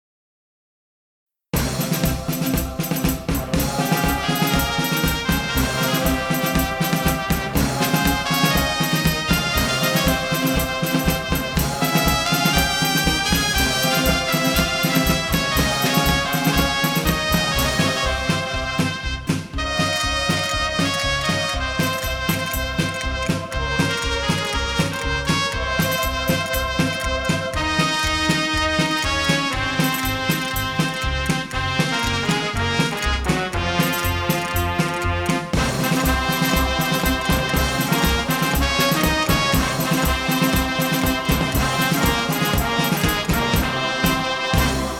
二、体育舞蹈(拉丁舞)：
1、西班牙斗牛：